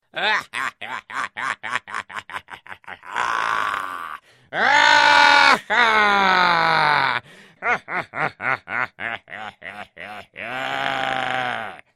Звуки злодея
Зловещий звук злодея из мультфильма с жутким хохотом